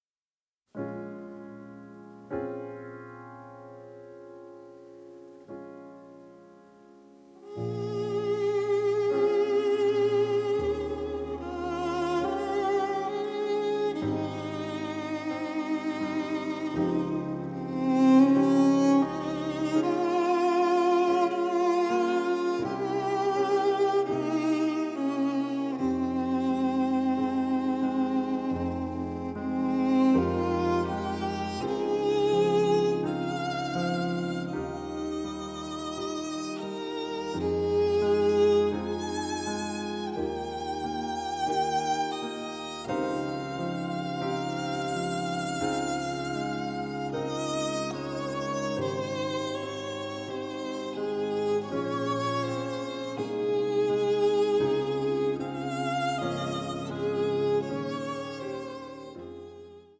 melancholic passages with atonal and dissonant moments